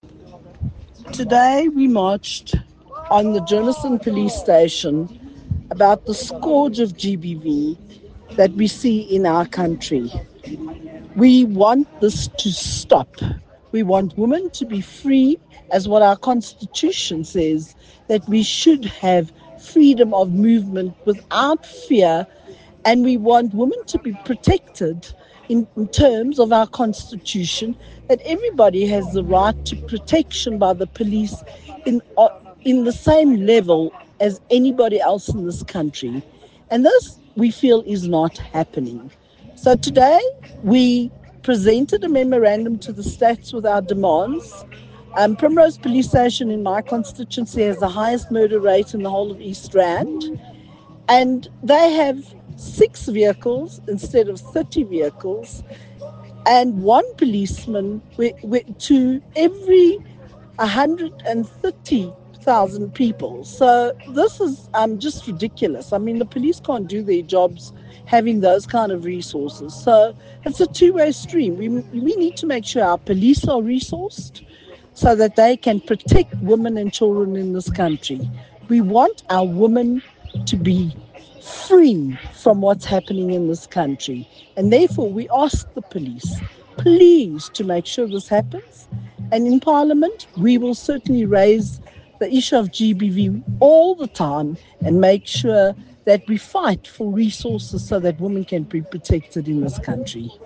Note to Editors: Please find English soundbite
Michele-Clark-MP_ENG_GBV-must-end.mp3